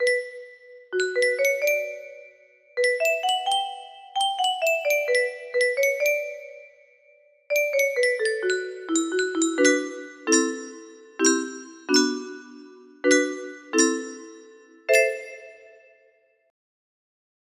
wwwwwww music box melody